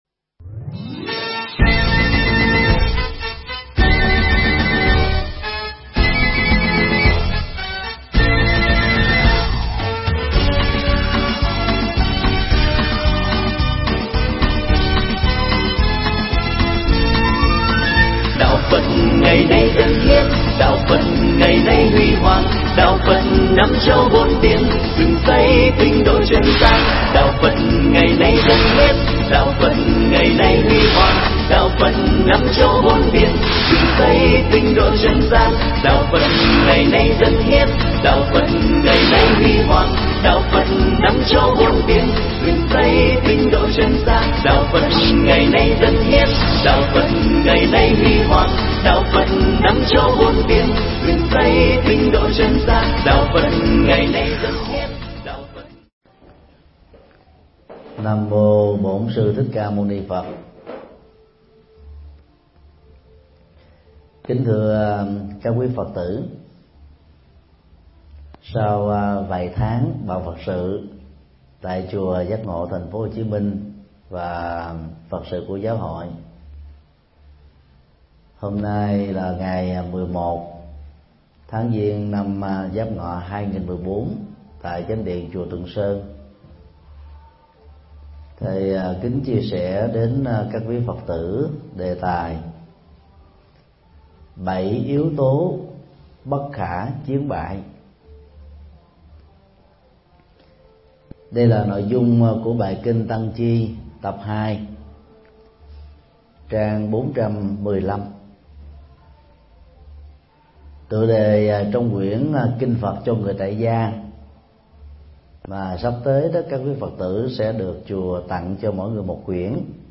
Mp3 Bài giảng Bảy yếu tố bất khả chiến bại được thầy Thích Nhật Từ Giảng tại chùa Tượng Sơn, Hà Tĩnh, ngày 12 tháng 02 năm 2014